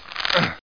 ropegrab.mp3